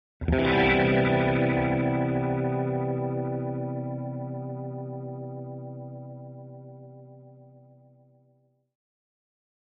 Guitar Leslie Major Chord